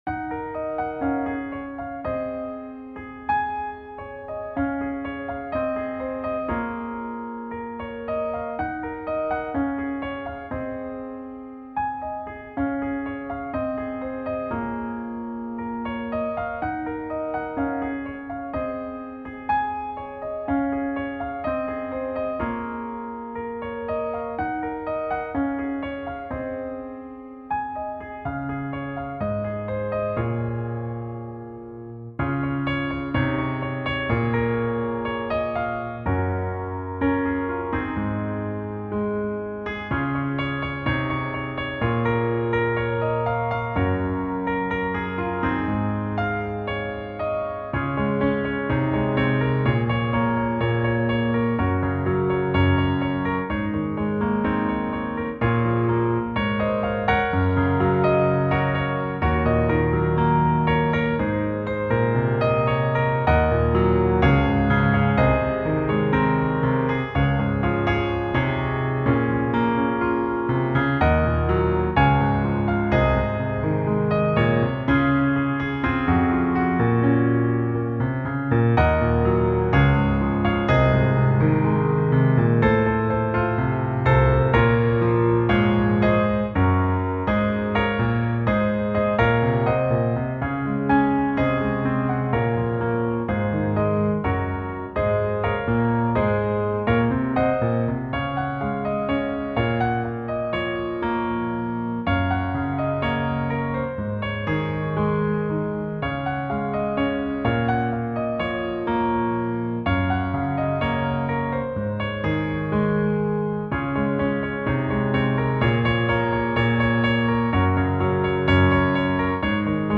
Early Intermediate Solo